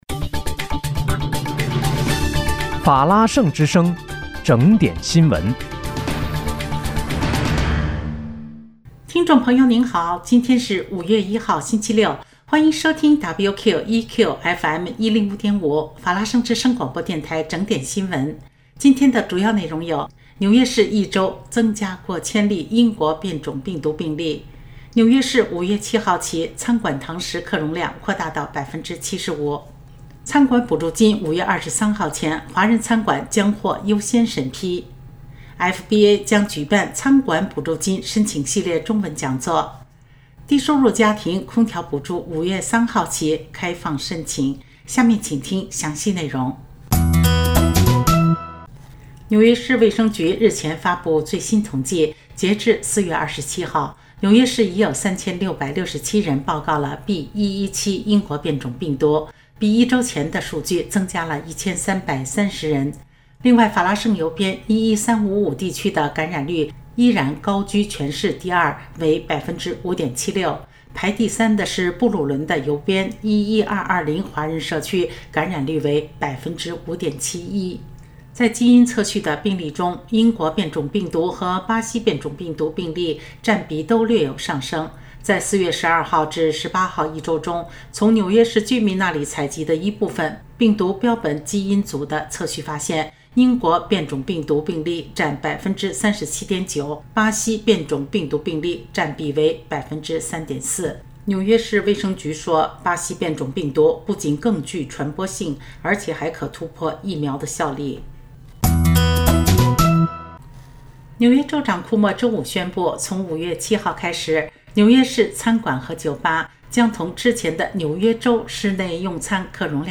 5月1日（星期六）纽约整点新闻